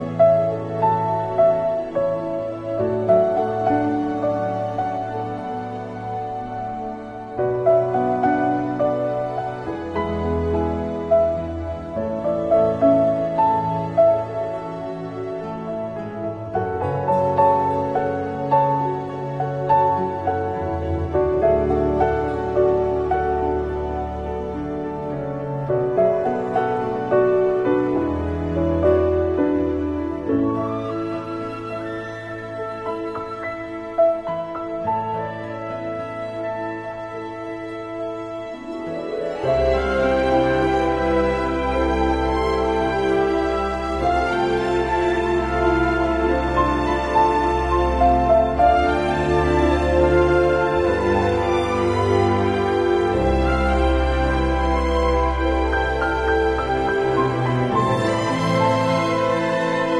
film music